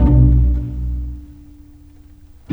Rock-Pop 09 Pizzicato 06.wav